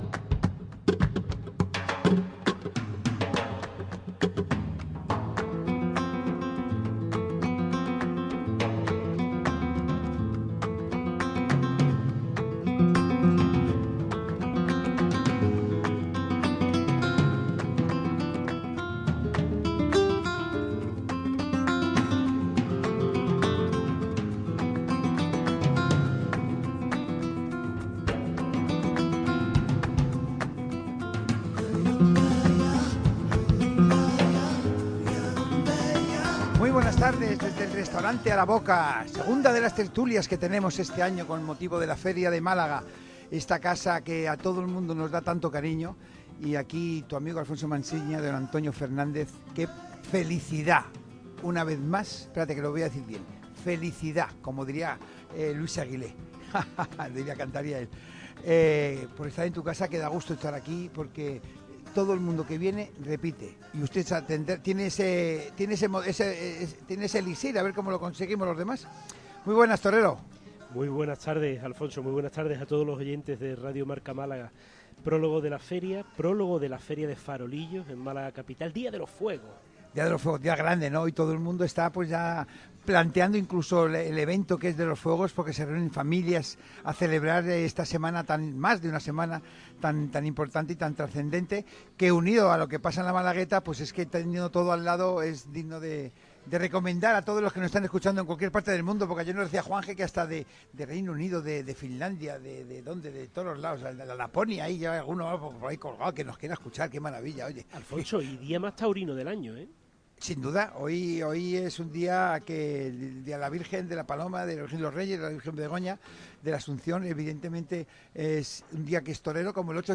El podcast de la tertulia del viernes 15 de agosto de 2025